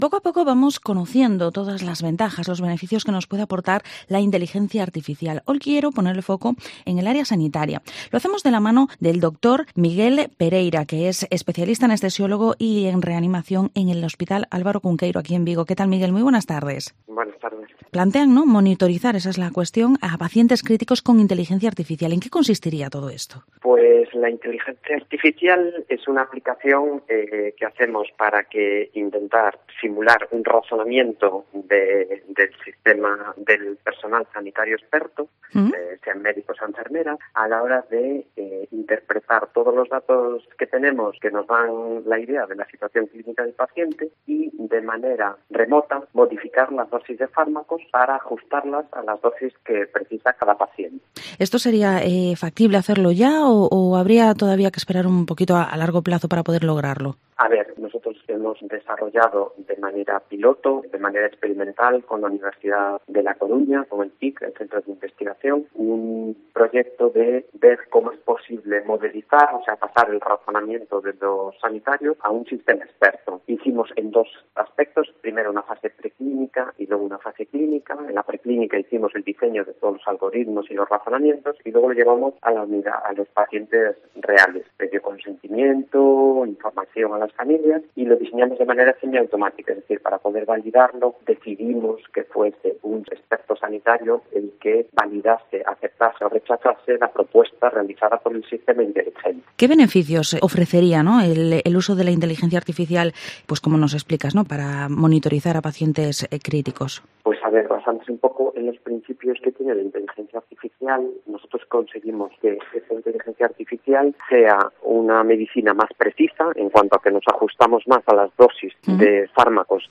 Vigo Entrevista ¿Cómo puede mejorar la IA el tratamiento de pacientes?